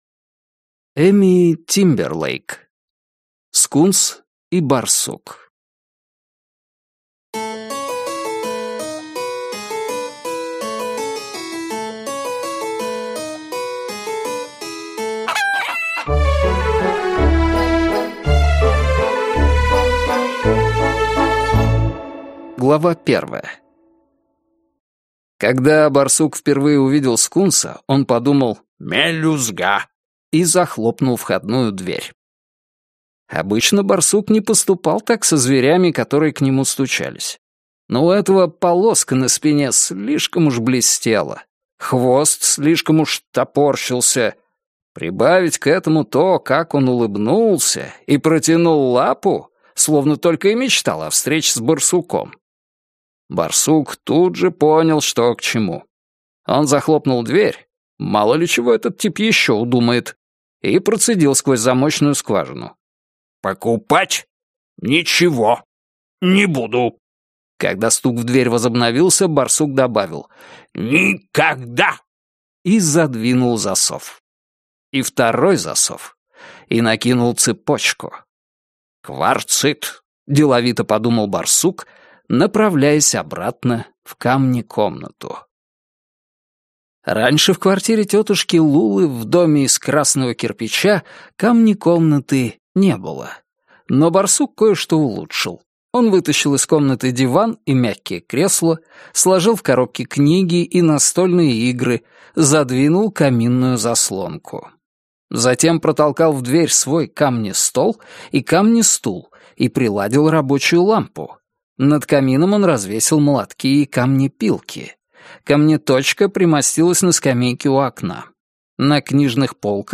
Аудиокнига Скунс и Барсук | Библиотека аудиокниг